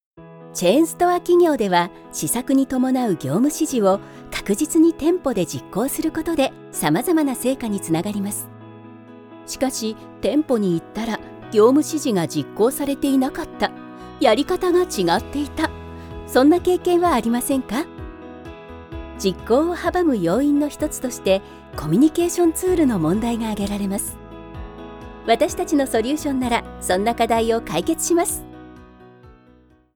Vidéos explicatives
Sa voix douce, claire et attachante est très polyvalente, ce qui en fait le choix incontournable de nombreuses grandes entreprises et organismes gouvernementaux.